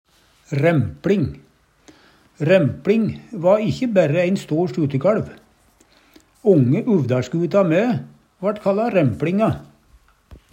rempLing - Numedalsmål (en-US)